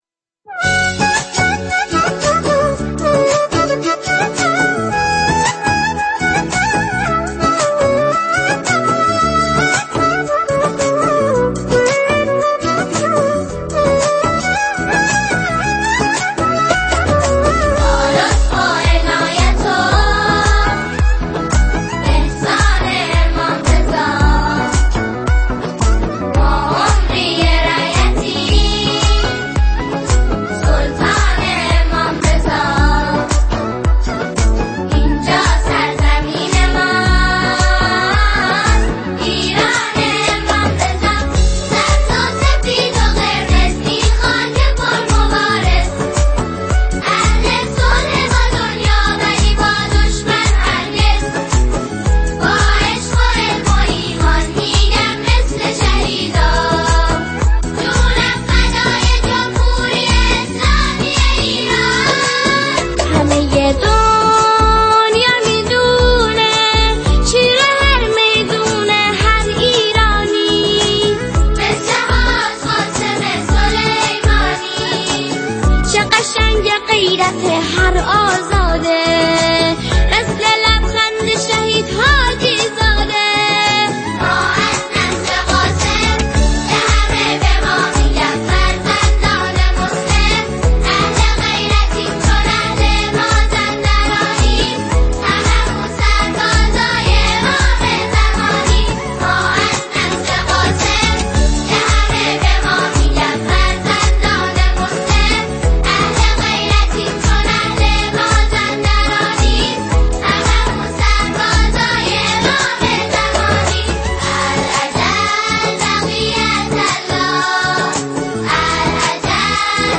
ژانر: سرود ، سرود انقلابی ، سرود مناسبتی